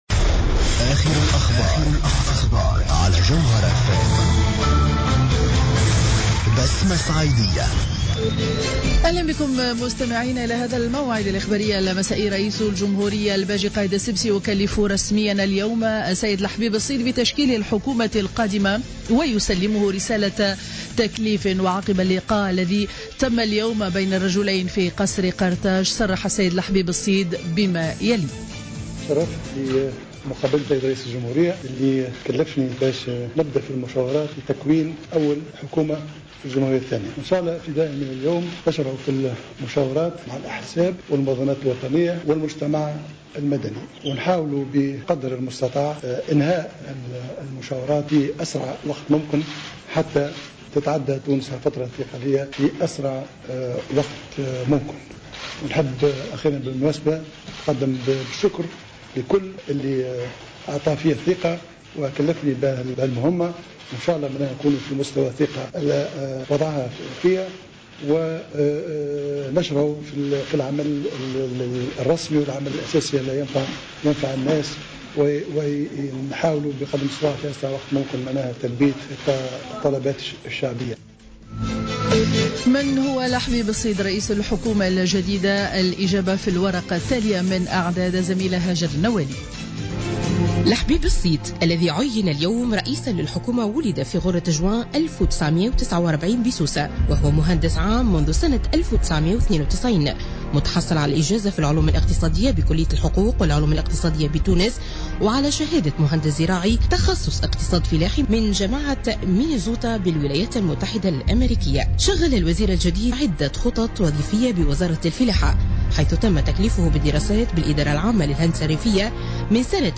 نشرة أخبار السابعة مساء ليوم 05-01-15